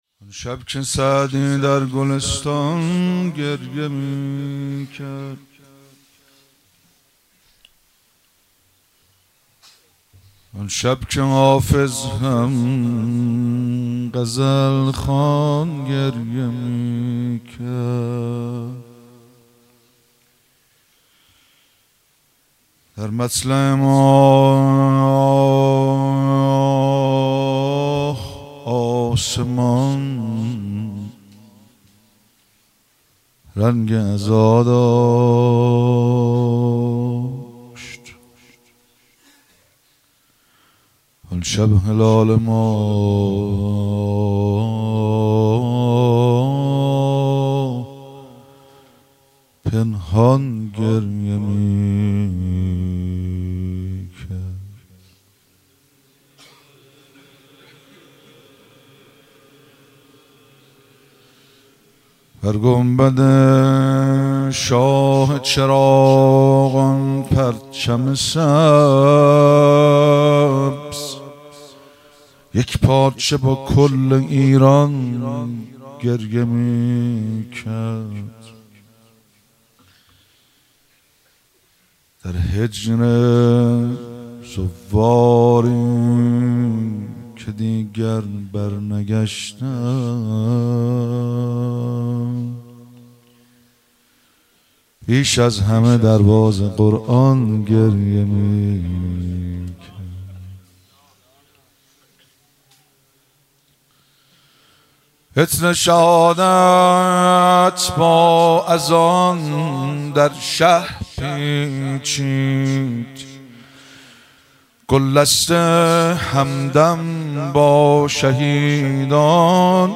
مراسم عزاداری وفات حضرت فاطمه معصومه (س)- آبان 1401